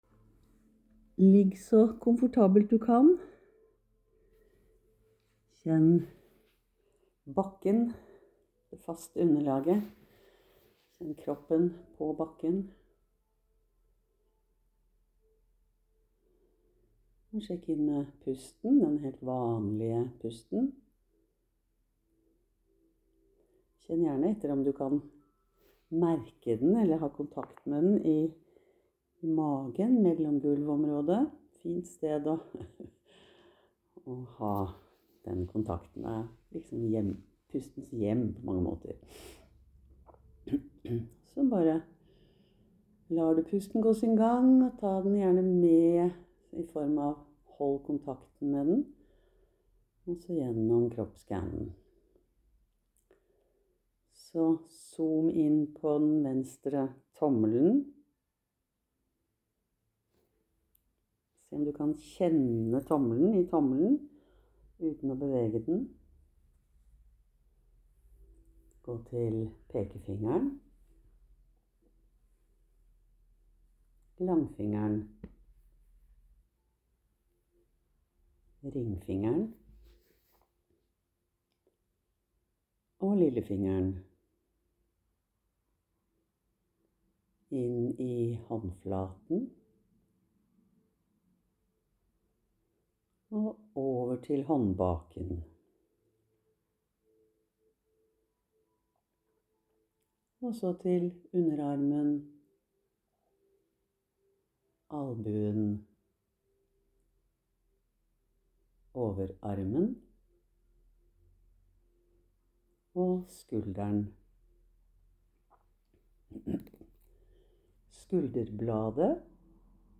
Lydkvaliteten er litt varierende, men håper de fungerer:-)